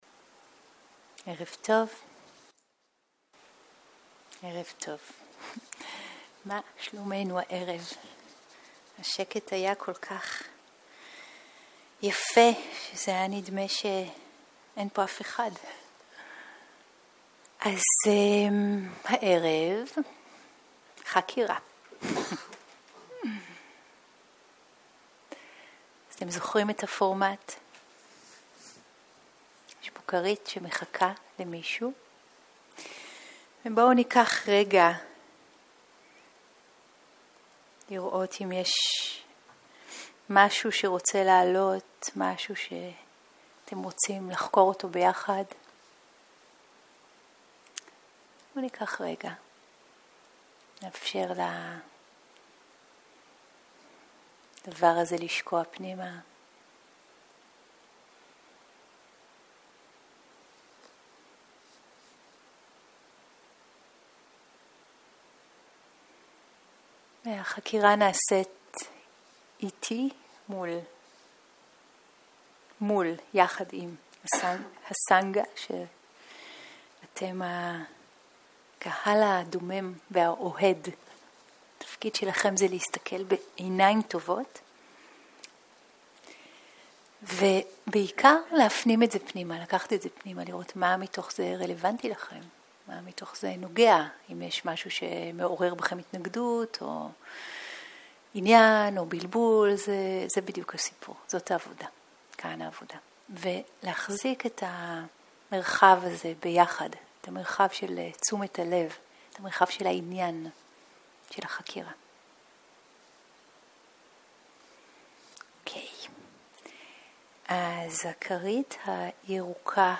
סוג ההקלטה: חקירה